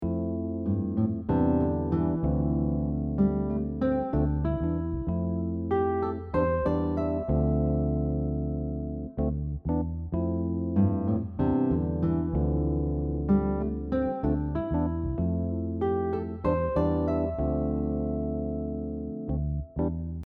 In example 4, we’re using the connected shape 1 again, but this time to create a short riff using the scale notes. The idea is then repeated higher up on the fretboard, helping you hear and visualize how the same phrase can move across positions.